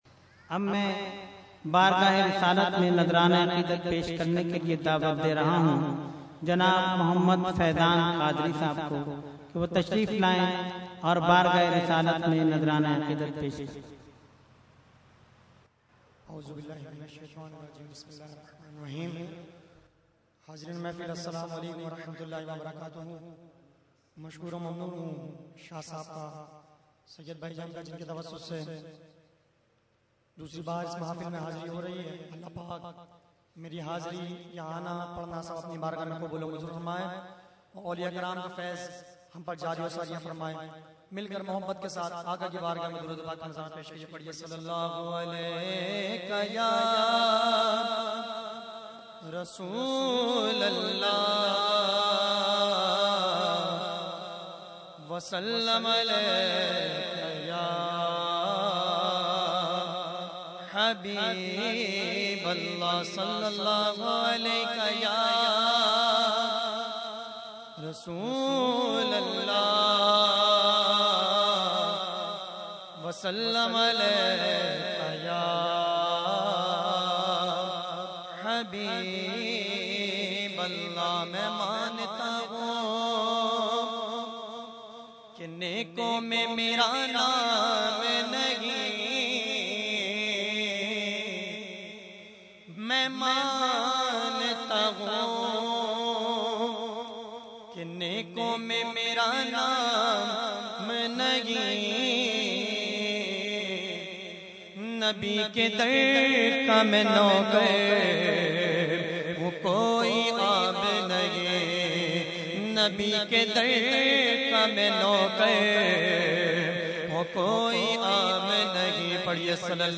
Category : Naat | Language : UrduEvent : Urs Ashraful Mashaikh 2012